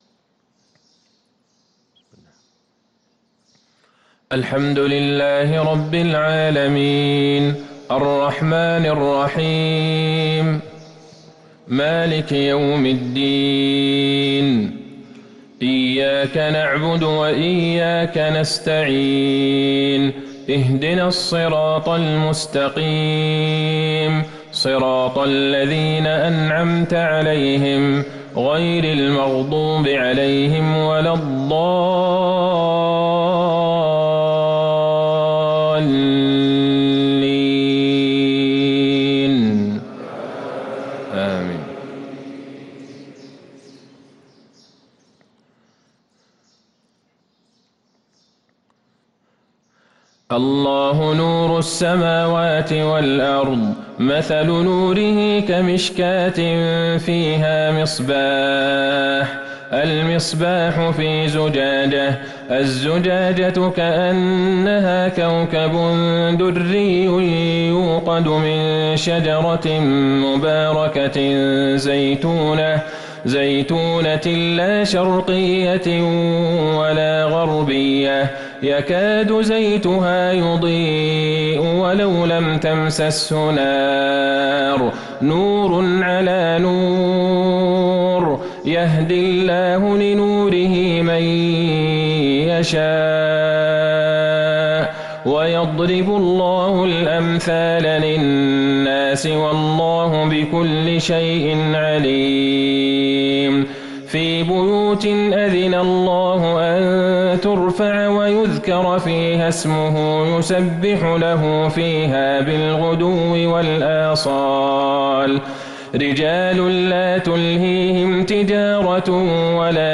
صلاة الفجر للقارئ عبدالله البعيجان 4 رجب 1444 هـ